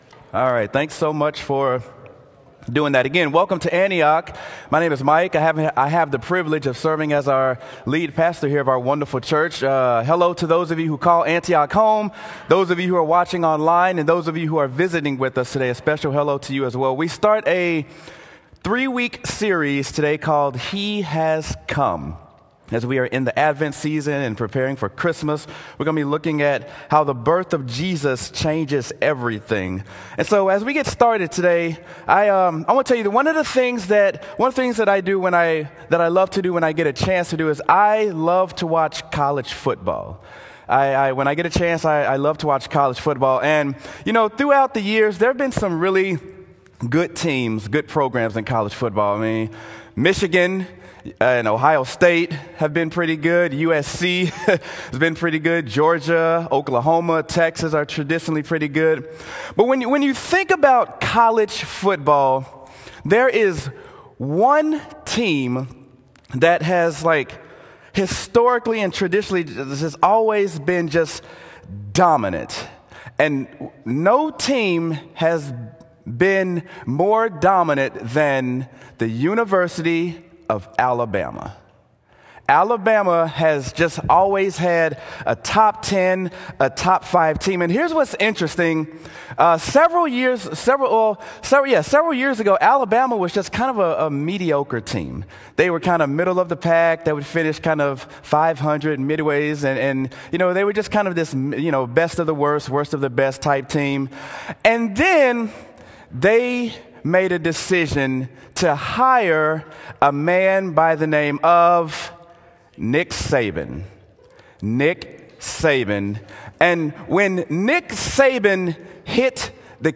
Sermon: He Has Come: Jesus Changes Everything
sermon-he-has-come-jesus-changes-everything.m4a